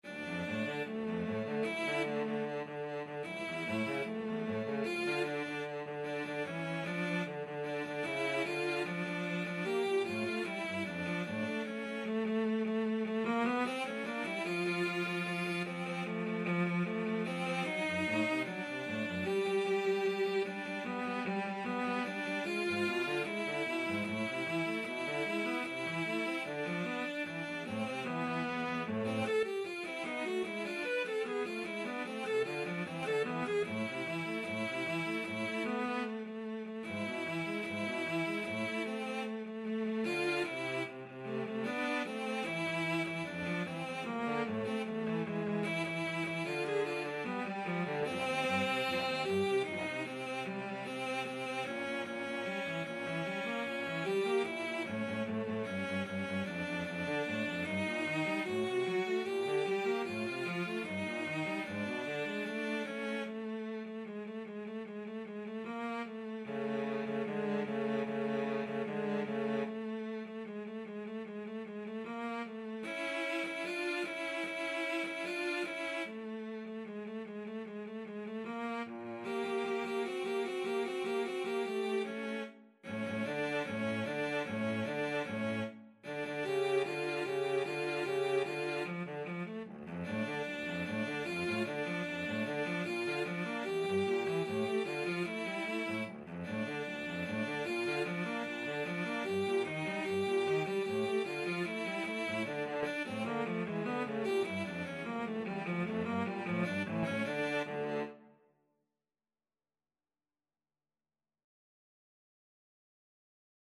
Classical (View more Classical Cello Duet Music)